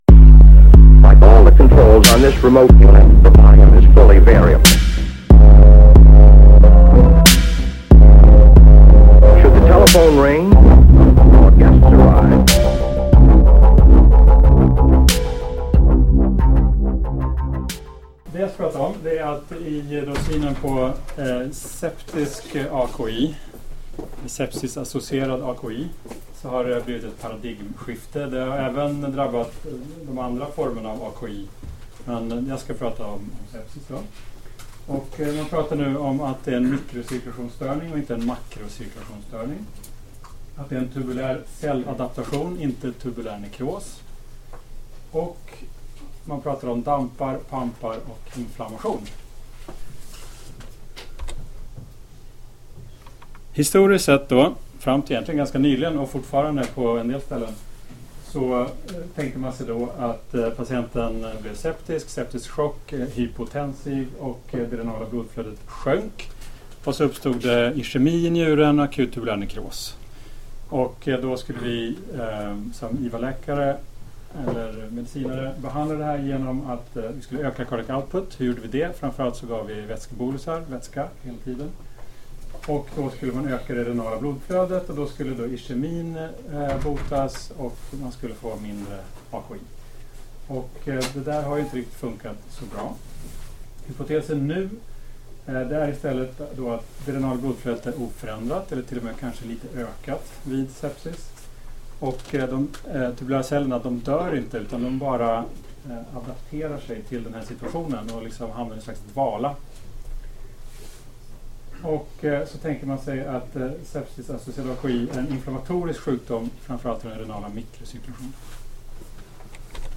Föreläsningen hölls på Dialyskursen på Karolinska 2016.